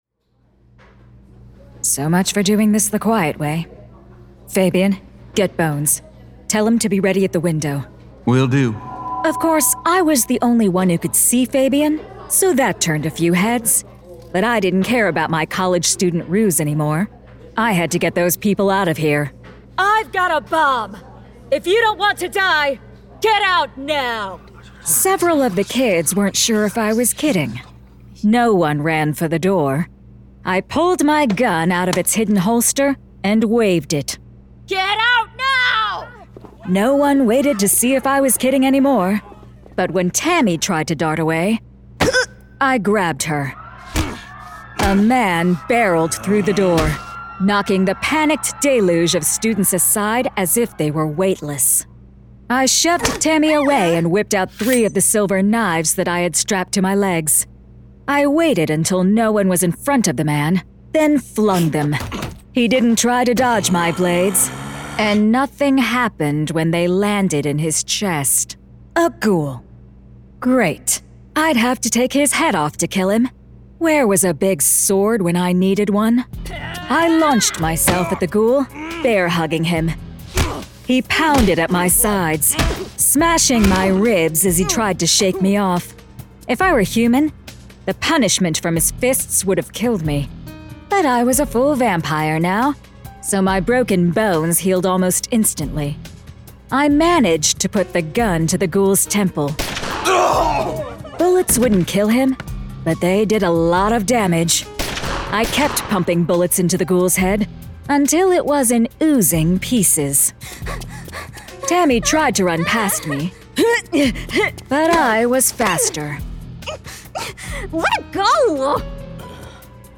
Full Cast. Cinematic Music. Sound Effects.
[Dramatized Adaptation]
Genre: Fantasy Romance